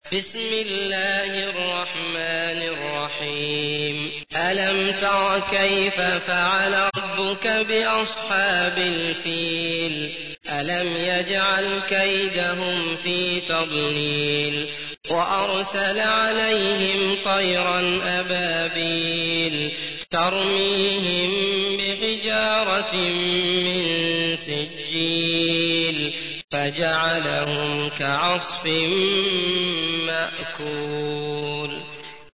تحميل : 105. سورة الفيل / القارئ عبد الله المطرود / القرآن الكريم / موقع يا حسين